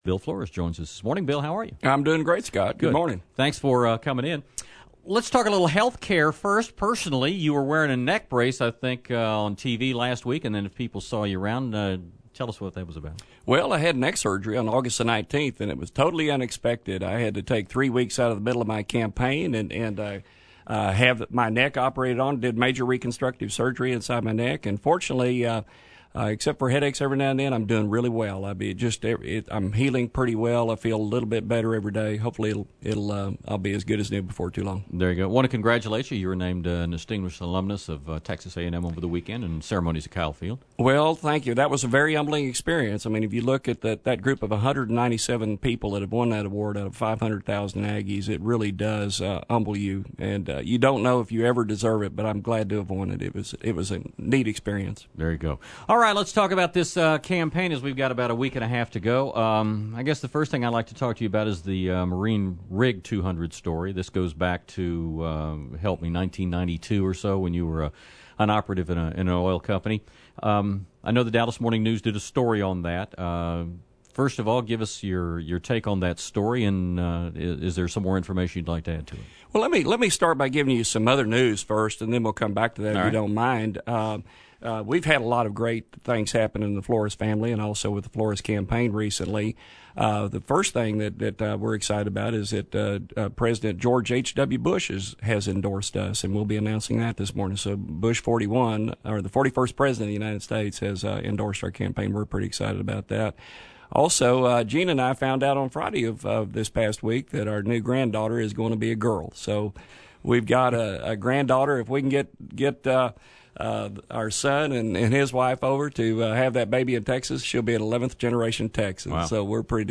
Republican Congressional Candidate Bill Flores was a guest on The Infomaniacs on Monday, October 18.